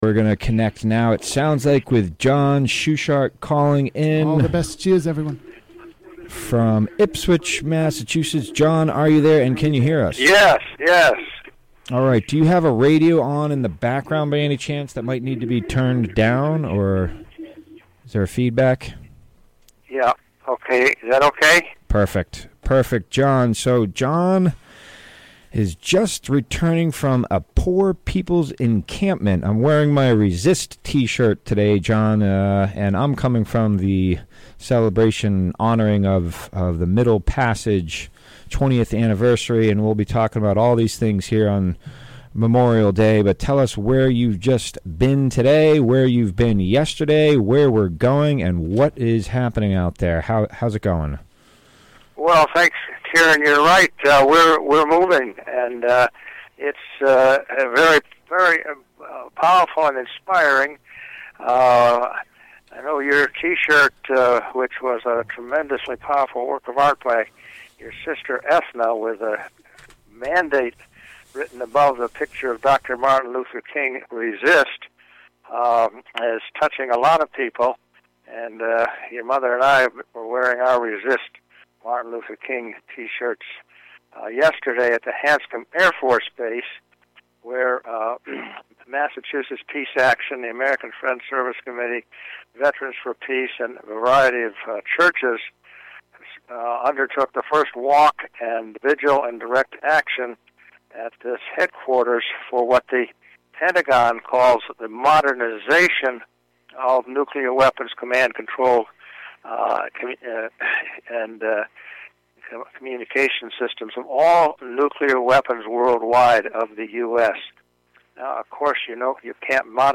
Recorded live on the WGXC Afternoon show on May 28, 2018.